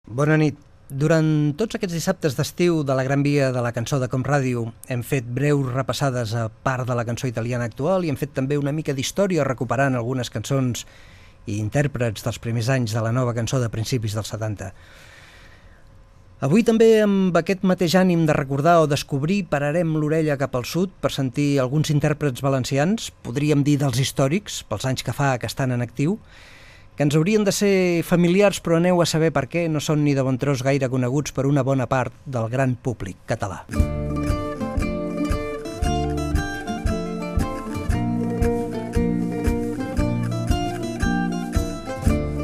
Musical
Fragment extret de l'arxiu sonor de COM Ràdio.